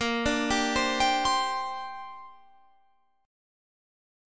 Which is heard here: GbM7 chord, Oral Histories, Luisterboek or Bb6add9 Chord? Bb6add9 Chord